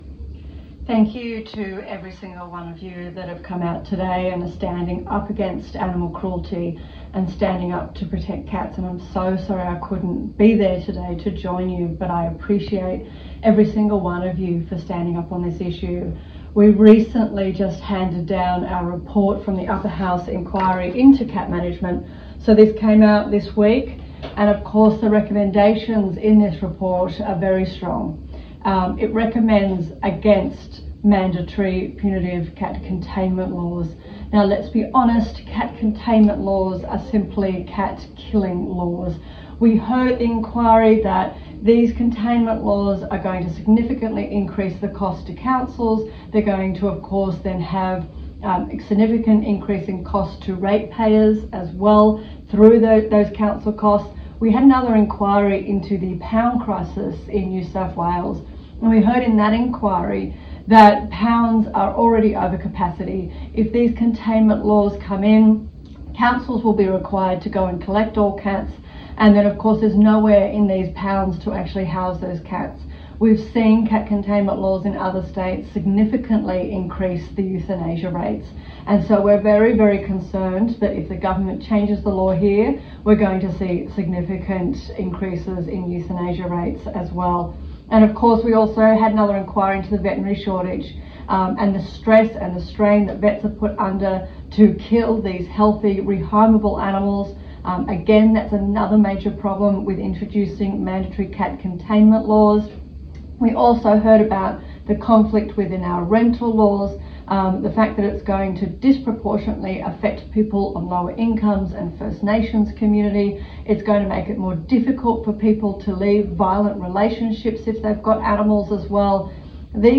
Emma Hurst Speech Cats _ received 29 AugDownload